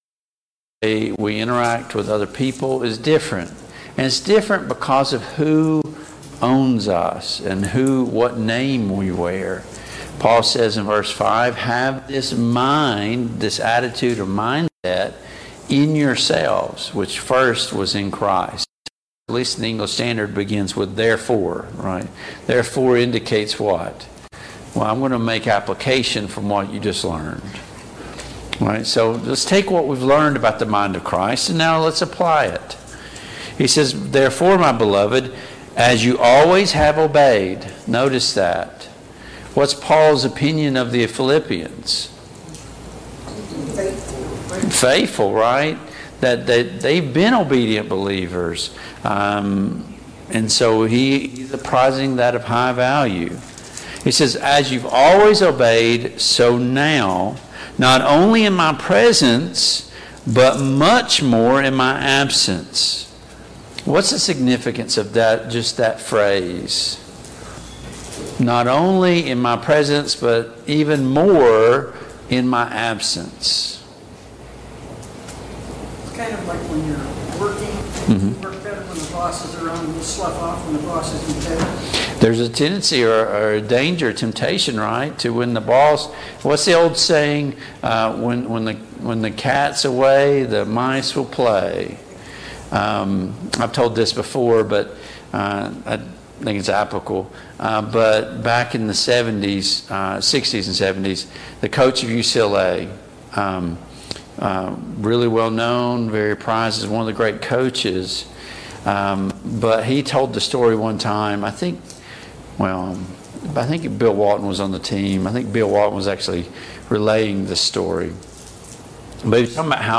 Passage: Philippians 2:12-18 Service Type: Mid-Week Bible Study Download Files Notes « 33.